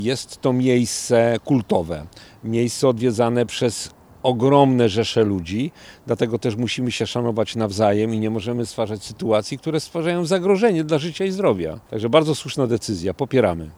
Sleza-2-burmistrz.mp3